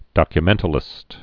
(dŏkyə-mĕntl-ĭst)